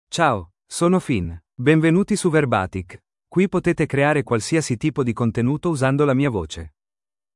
FinnMale Italian AI voice
Finn is a male AI voice for Italian (Italy).
Voice sample
Male
Finn delivers clear pronunciation with authentic Italy Italian intonation, making your content sound professionally produced.